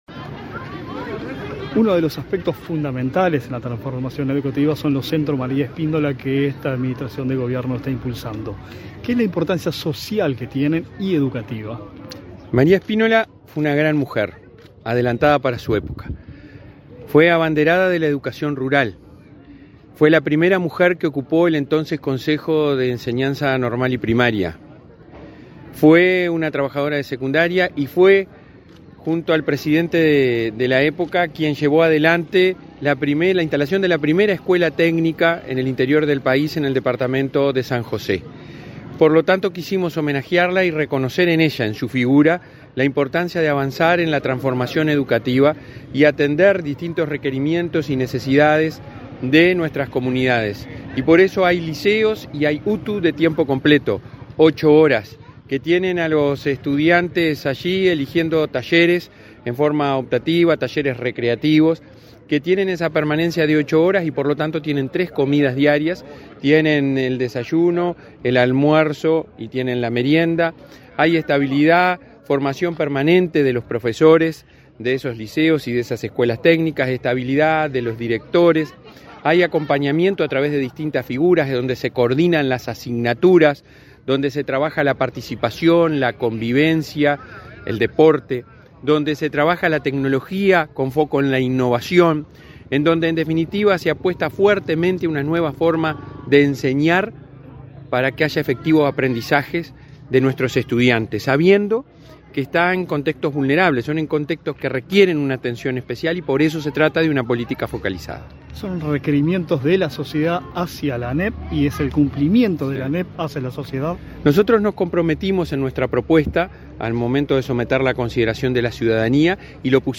El presidente de Administración Nacional de Educación Pública (ANEP), Robert Silva, explicó el proceso, en diálogo con Comunicación Presidencial.